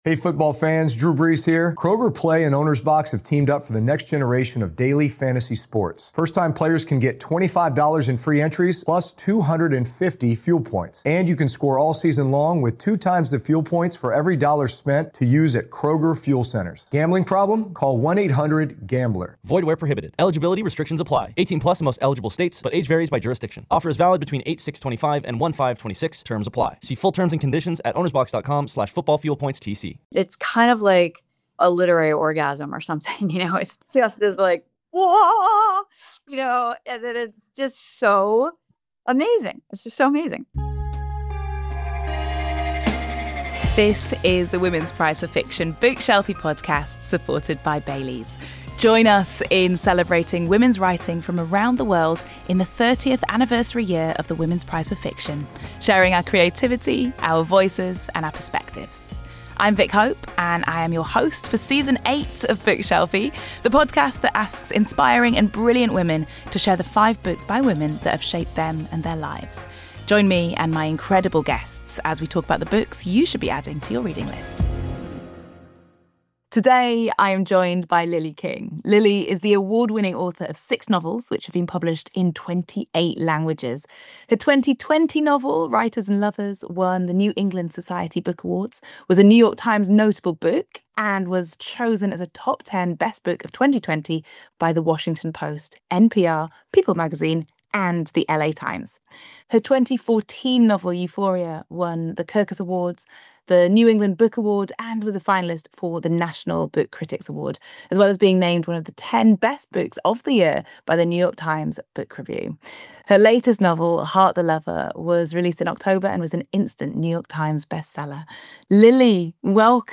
Award-winning author Lily King joins us from the road during her book tour to explore how women finding themselves and love are such big topics in her favourite books.